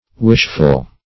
Wishful \Wish"ful\, a. [Cf. Wistful.]